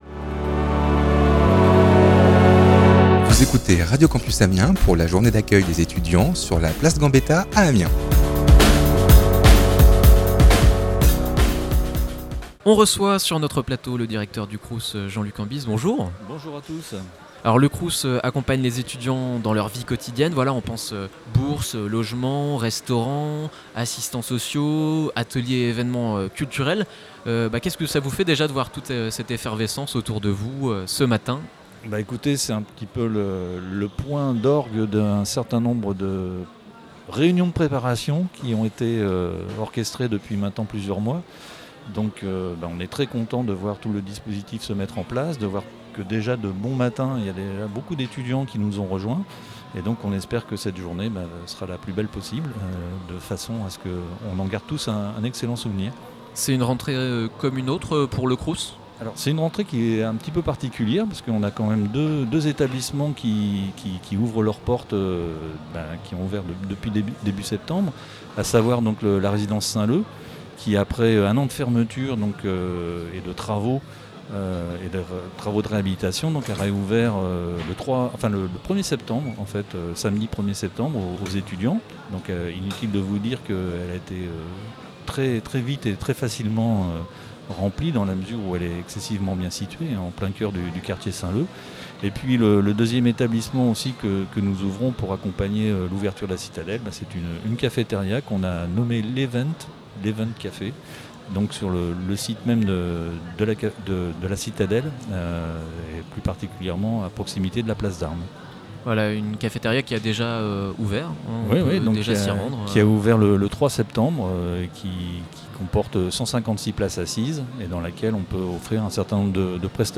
Le but : permettre aux jeunes de rencontrer l’ensemble des acteurs de la vie étudiante pour faciliter leur intégration. Retrouvez ici les interviews réalisées sur notre plateau et les témoignages d’étudiants recueillis au micro.
• Benjamin Prince, conseiller régional des Hauts-de-France délégué à la Vie Étudiante
• Témoignages d’étudiants